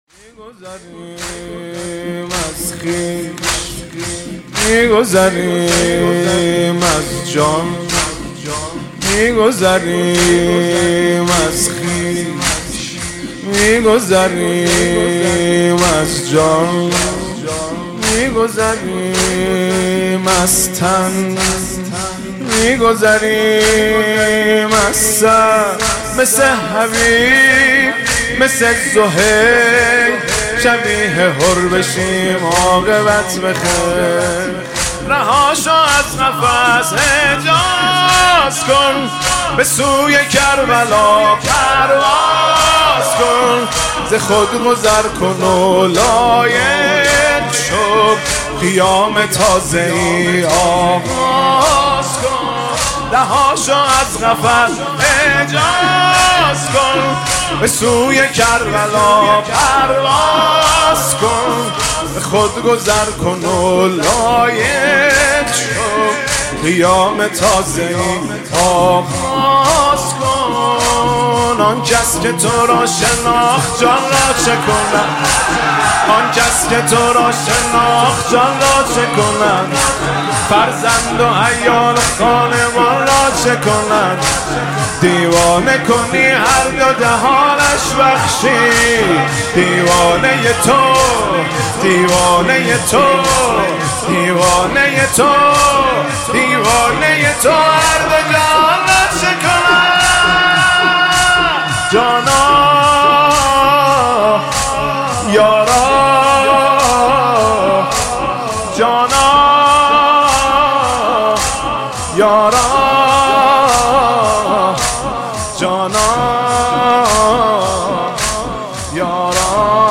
مداحی «می گذریم از جان» با نوای مهدی رسولی (کلیپ، صوت، متن)
دانلود مداحی دلنشین «می گذریم از جان» با نوای حاج مهدی رسولی به همراه متن شعر
نماهنگ